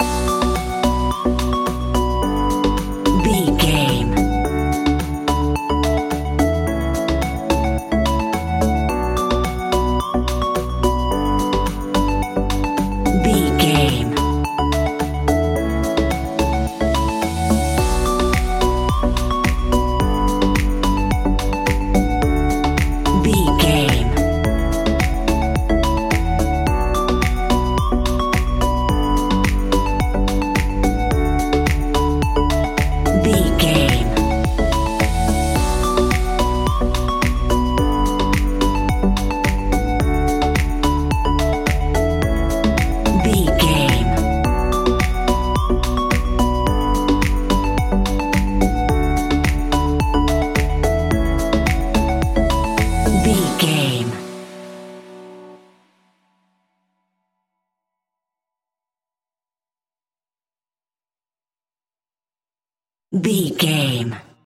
Aeolian/Minor
uplifting
energetic
bouncy
synthesiser
drum machine
electro house
funky house
synth bass